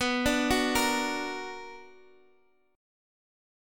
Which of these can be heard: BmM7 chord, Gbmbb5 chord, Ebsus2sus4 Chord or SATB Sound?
BmM7 chord